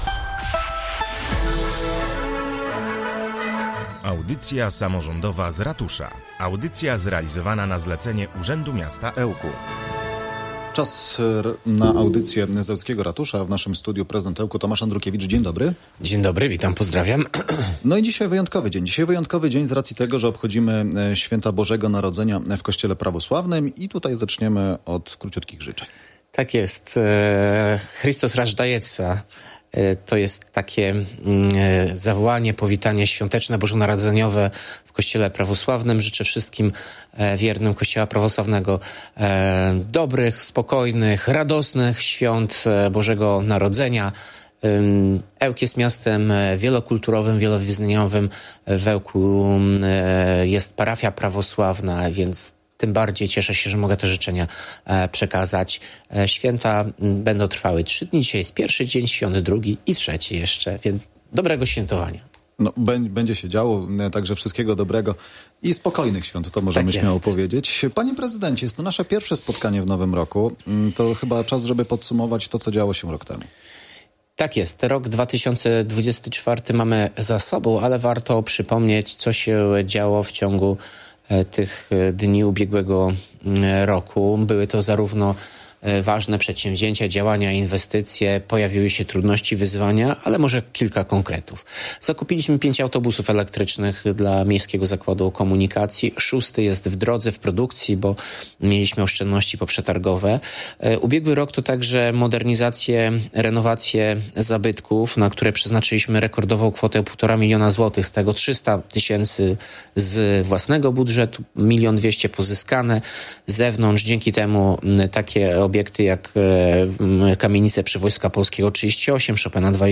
Tomasz Andrukiewicz, prezydent Ełku